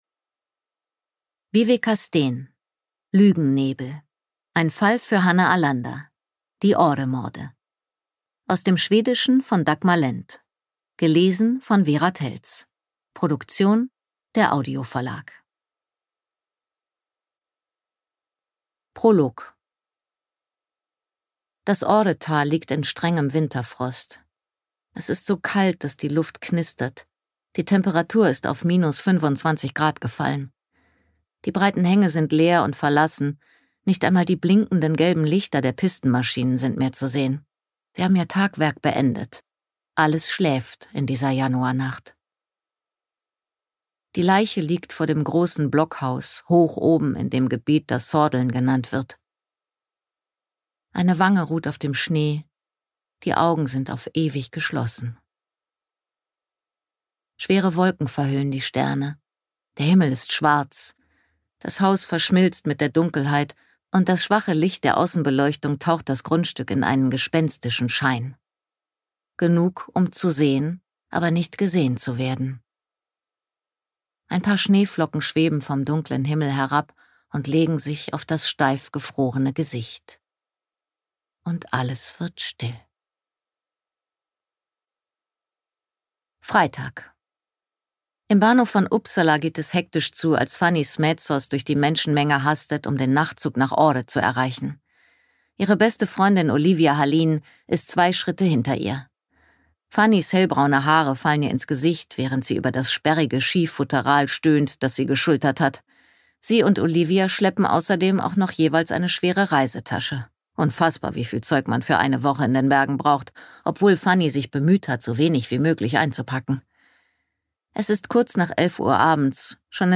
Hörbuch: Lügennebel.